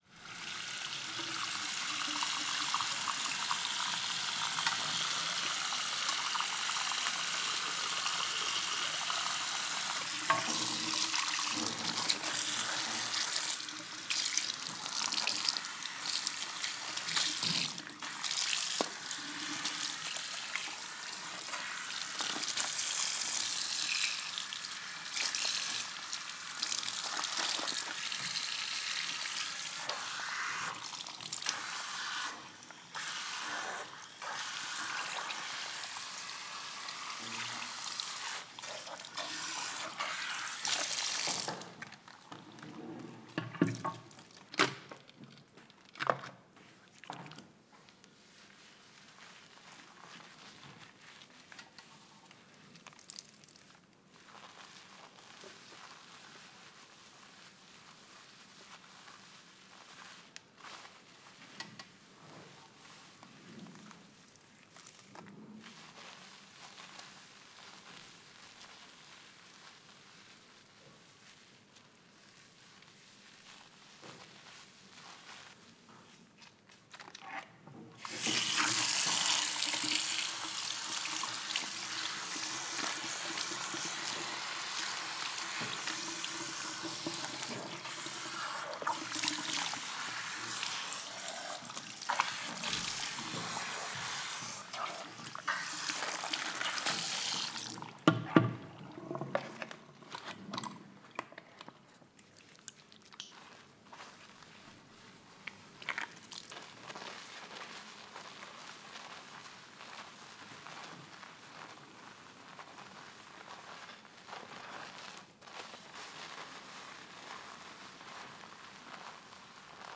Hair Washing Sound
household